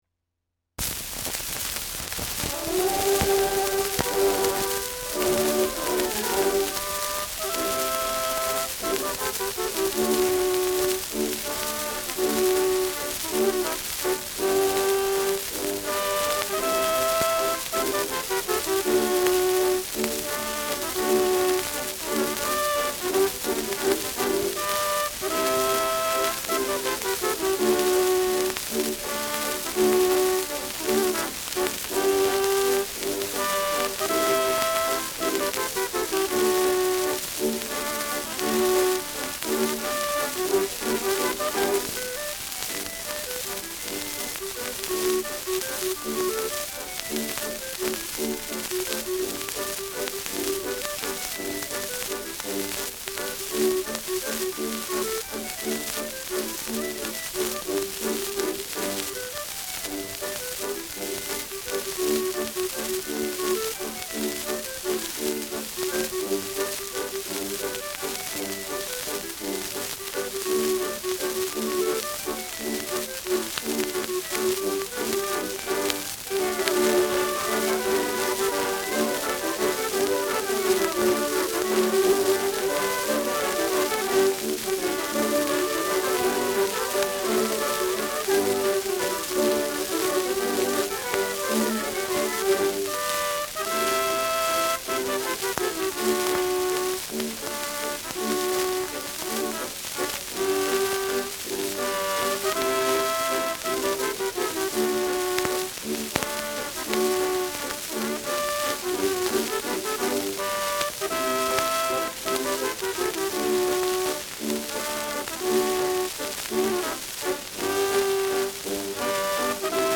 Schellackplatte
starkes Rauschen : abgespielt : leiert : gelegentliches Knacken : Nadelgeräusch : Tonnadel „rutscht“ bei 2’22’’ über einige Rillen : Knacken bei 2’25’’
Neues Münchener Concert-Orchester (Interpretation)